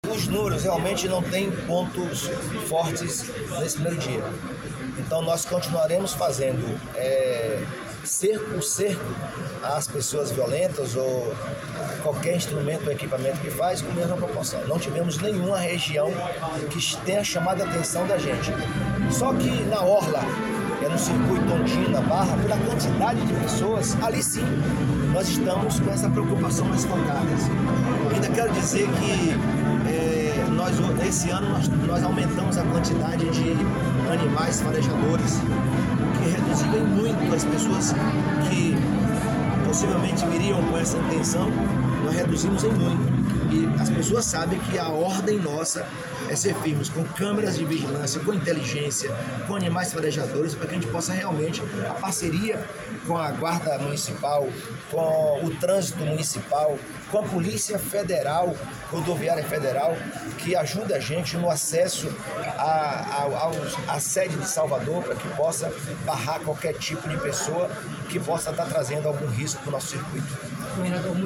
Jerônimo Rodrigues, faz balanço do primeiro dia de carnaval em Salvador